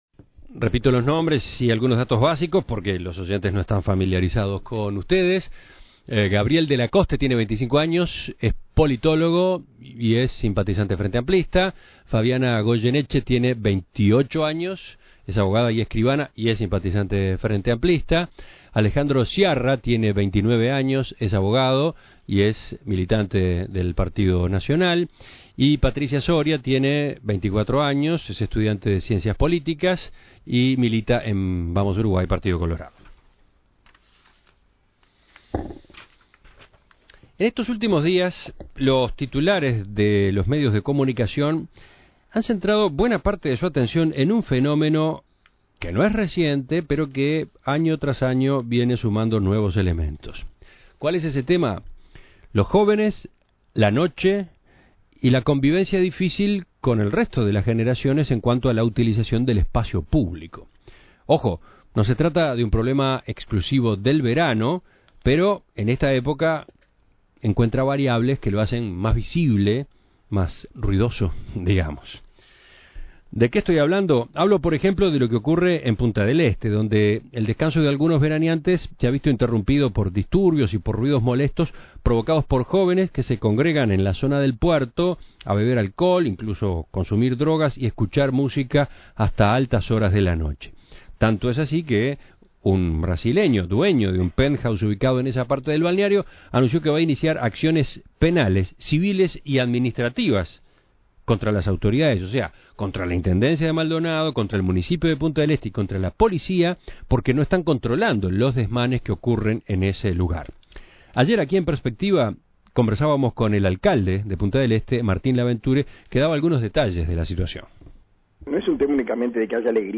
La Tertulia Los jóvenes, la noche y la difícil convivencia con el resto de las generaciones en la utilización del espacio público.